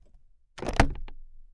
Part_Assembly_35.wav